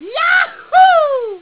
and now the tasty sound effects...
YAHOO!